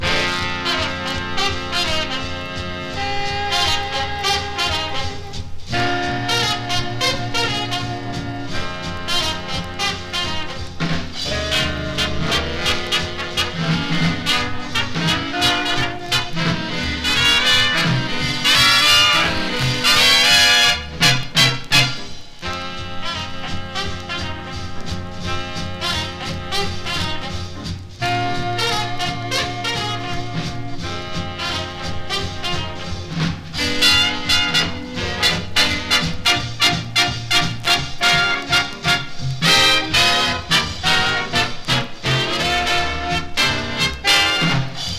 Jazz, Blues, Swing, Big Band　USA　12inchレコード　33rpm　Mono